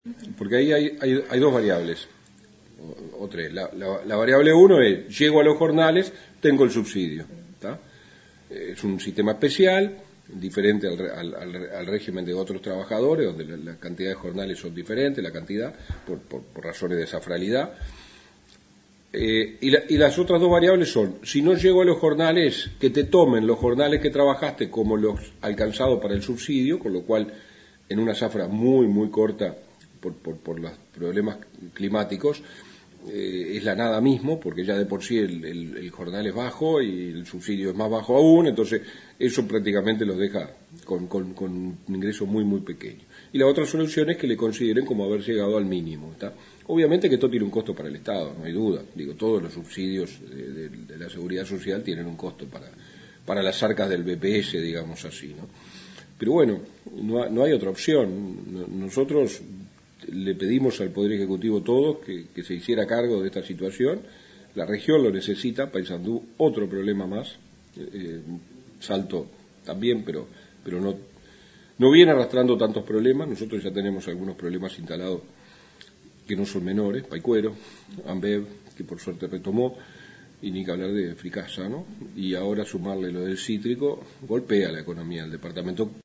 El diputado colorado Walter Verri explicó que hoy los trabajadores zafrales tienen un régimen especial, pero que en esta zafra, muy afectada por el clima, muchos no alcanzaron los jornales necesarios.
corresponsal en Paysandú